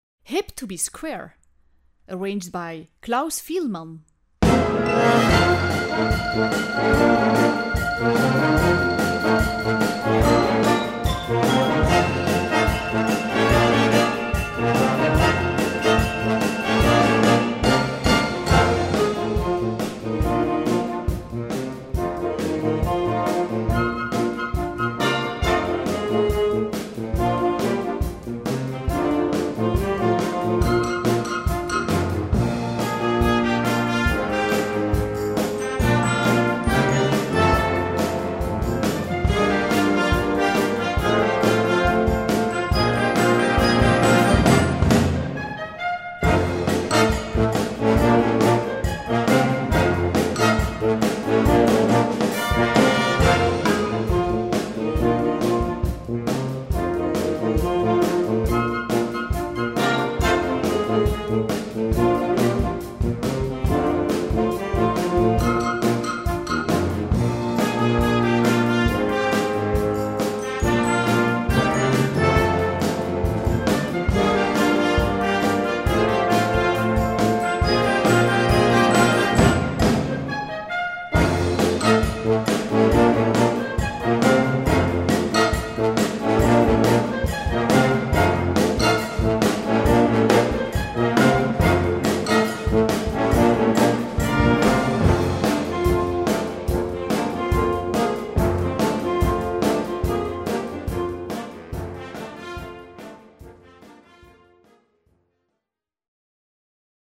Besetzung: Blasorchester
Der solide Rocksong